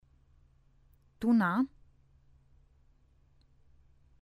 tună